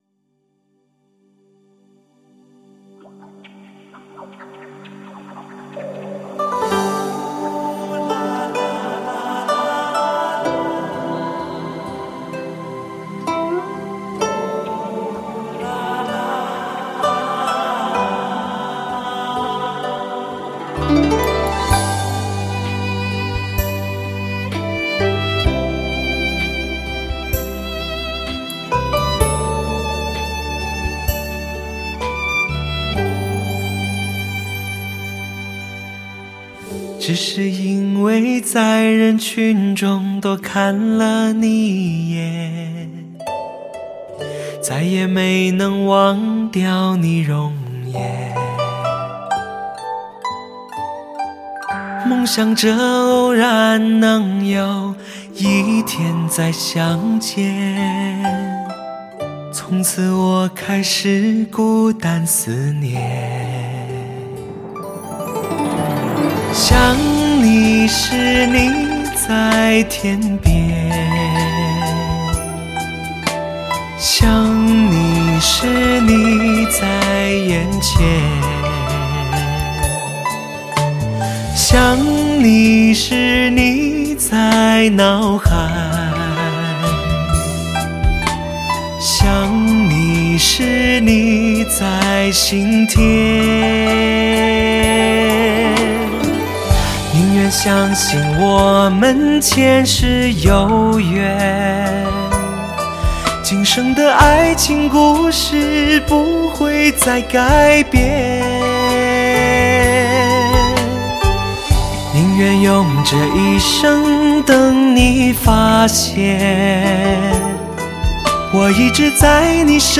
深情精选
都市生活的隐蔽情绪，深入骨髓优雅温柔。